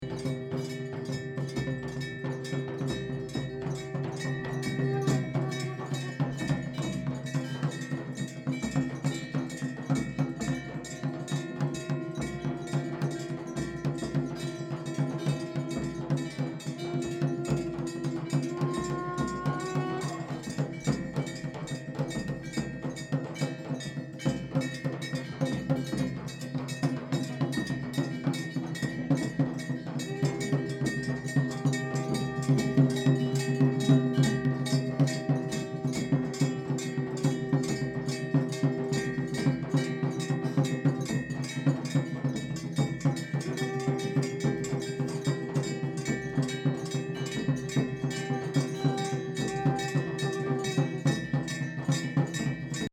01/12/2013 18:00 Le soir, nous allons voir le coucher de soleil en haut d'un sommet, où se trouve le temple Mata Ji. Beaucoup d'indiens sont réunis ici. Le soleil disparait entre deux crêtes, et tous se mettent à tapper dans des tambours et des cloches, et souffler dans des conques.
temple_cloches.mp3